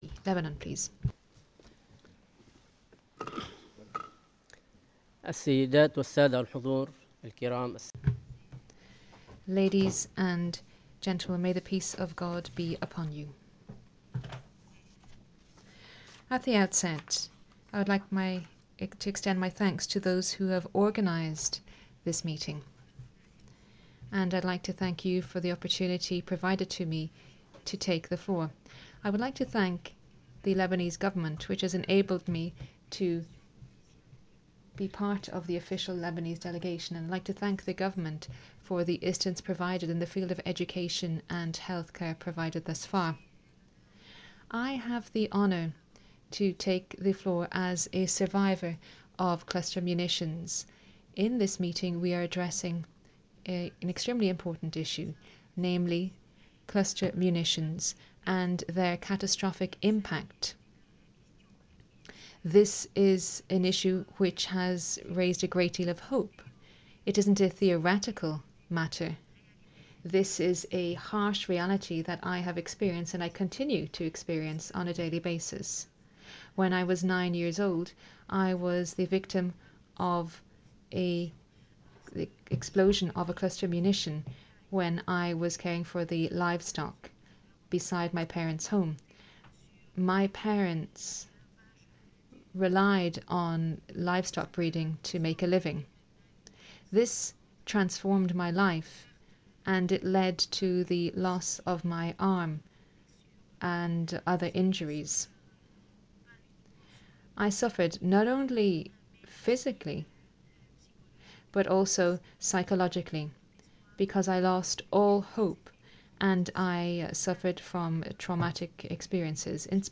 Lebanon-Cluster-Survivor-Statement-12MSP.mp3